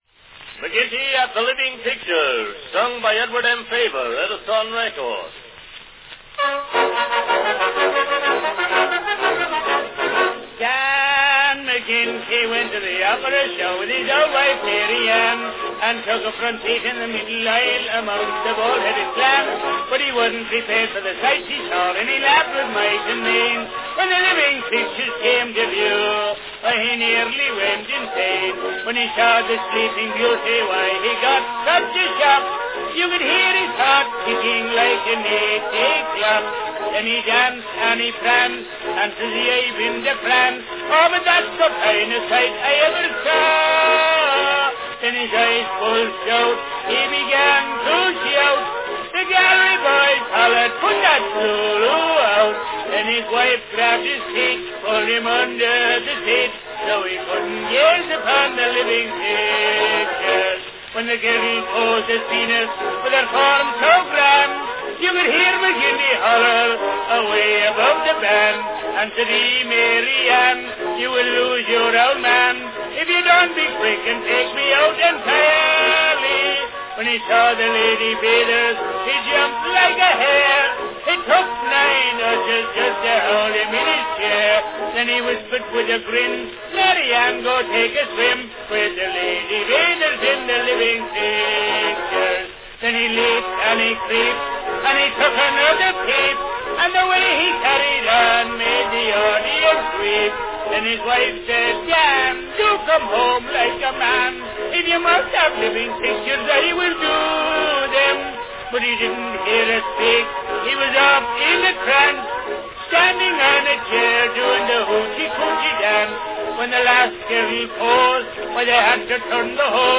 Category Comic song